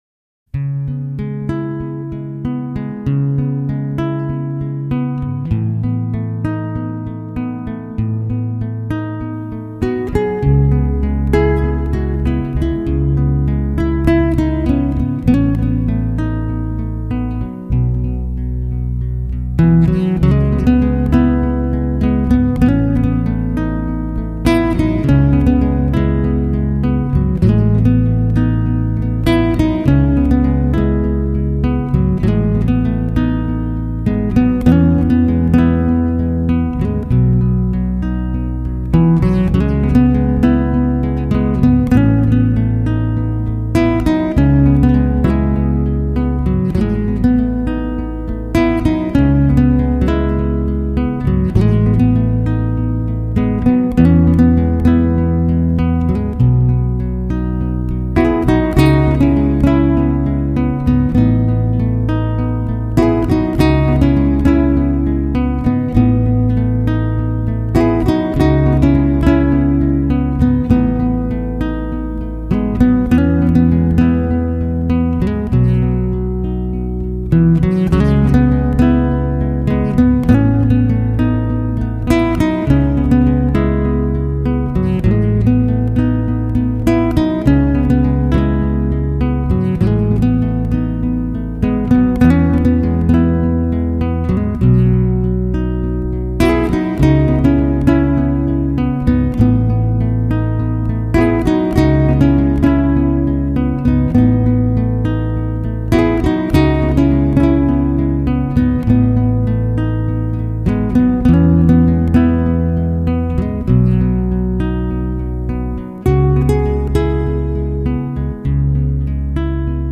风格：Instrumental, New Age, Relaxiation.